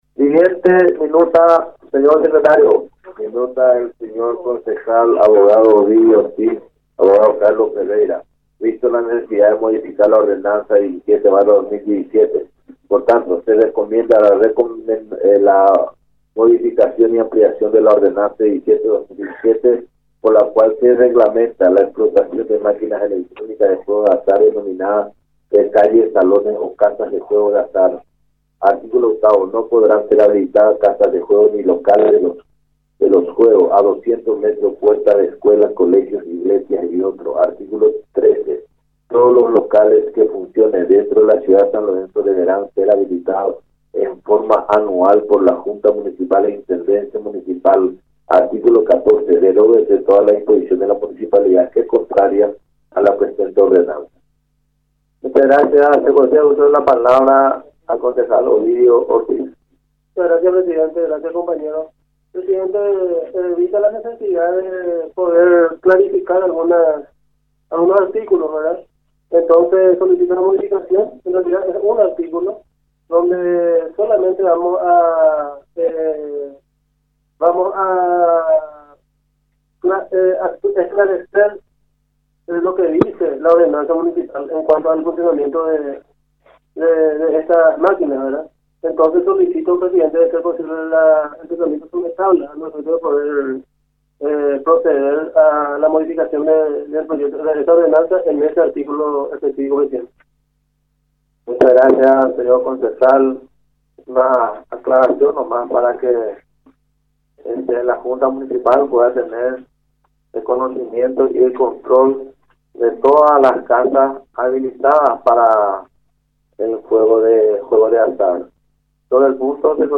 En el audio de la sesión ordinaria del pasado miércoles donde se trató el planteamiento de Ortiz y Ferreira, no se escucha a nadie mencionando el punto donde expresa que se puede admitir locales con tragamonedas dentro del mercado municipal.
Audio del momento de tratamiento de la propuesta (en ningún momento se oye que nombraron lo del mercado).